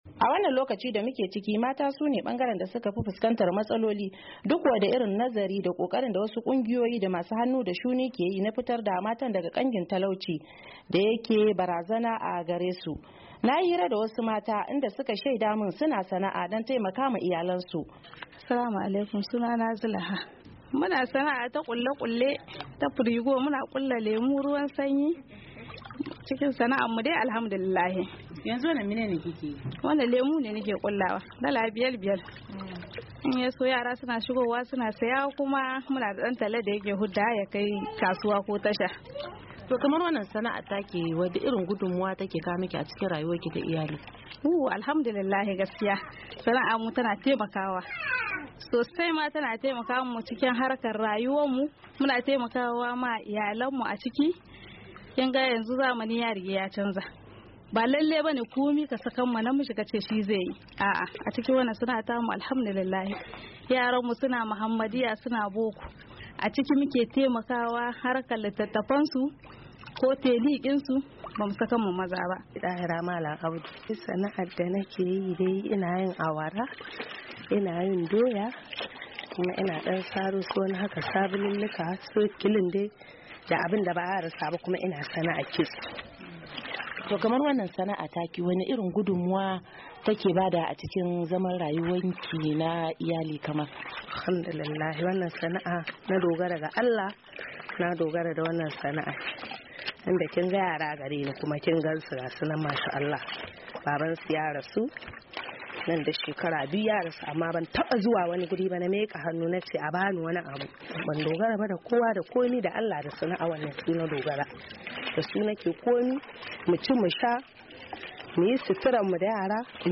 A firar da wasu mata suka yi da Muryar Amurka sun ce suna sana'o'i domin taimaka wa kansu da 'ya'yansu.